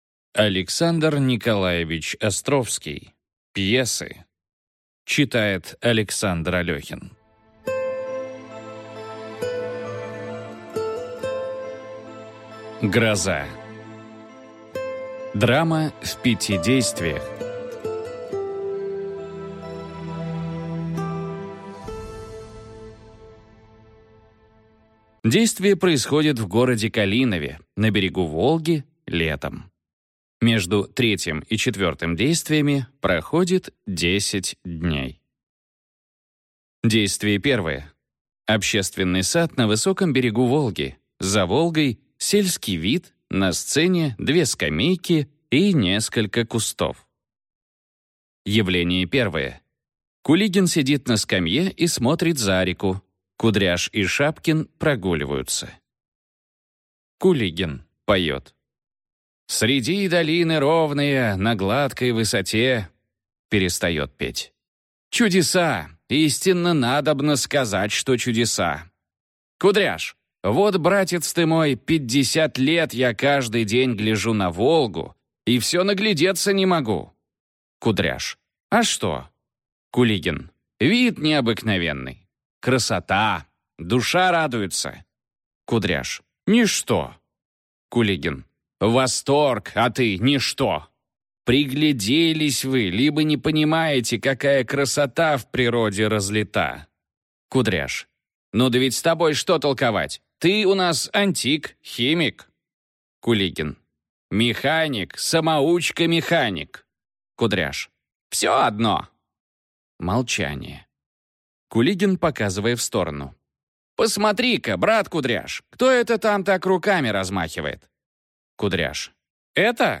Аудиокнига Гроза и другие пьесы | Библиотека аудиокниг